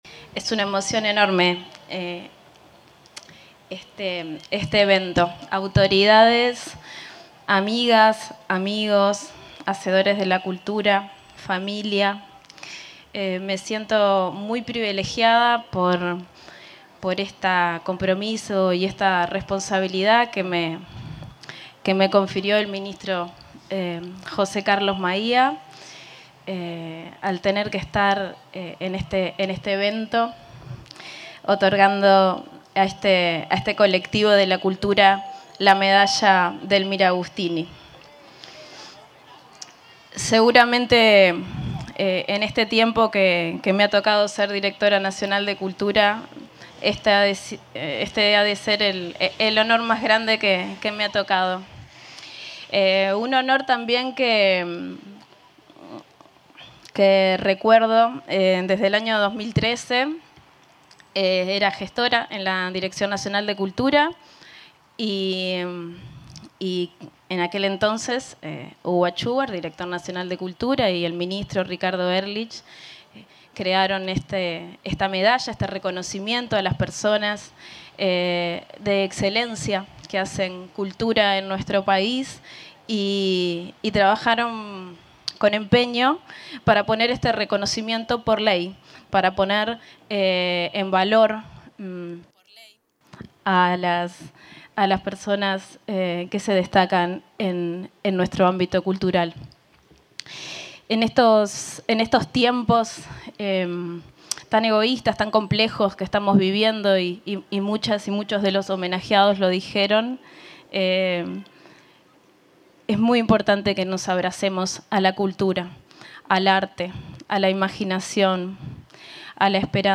El ministro de Educación y Cultura, José Carlos Mahía, y la directora nacional de Cultura, María Eugenia Vidal, hicieron uso de la palabra en la entrega de premios Delmira Agustina 2025